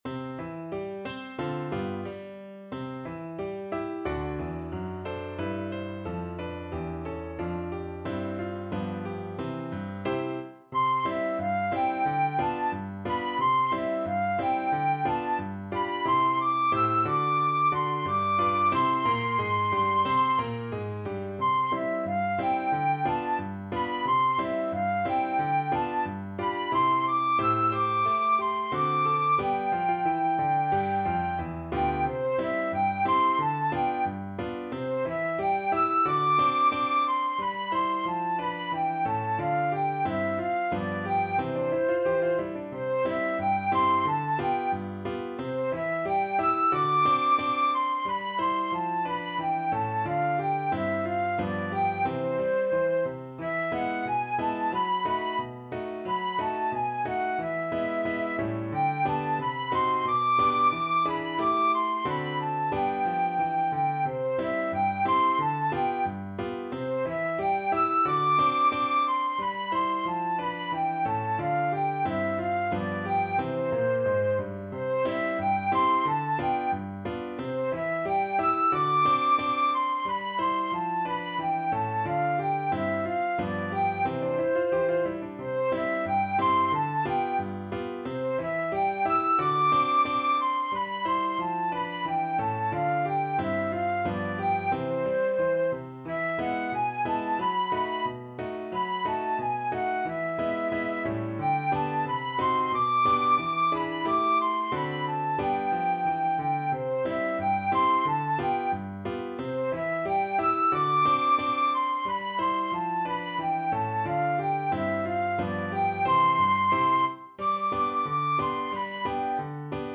2/2 (View more 2/2 Music)
=90 Fast and cheerful
Pop (View more Pop Flute Music)